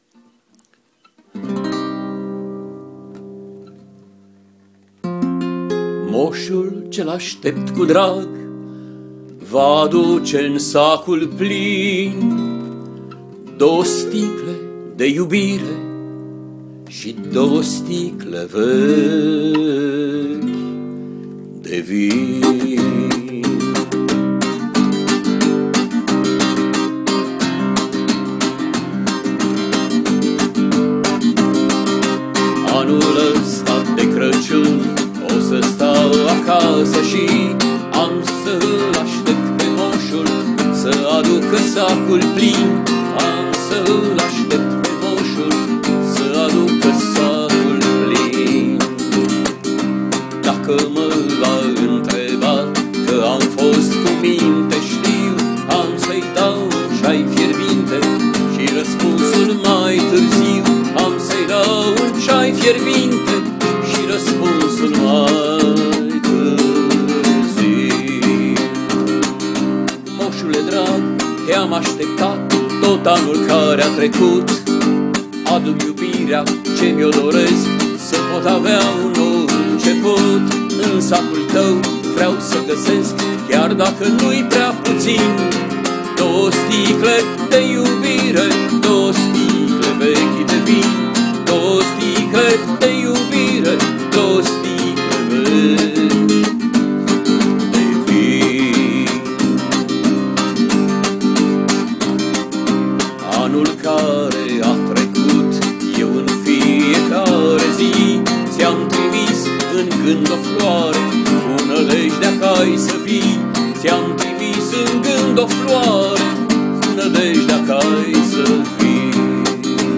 Iata un cantec scris anul trecut in ajun de Craciun… L-am inregistrat in conditii precare, cu telefonul mobil, neavand alte posibilitati deocamdata. Cu rugamintea de a scuza calitatea indoielnica a inregistrarii si faptul ca m-am mai incurcat putin… sper sa va placa!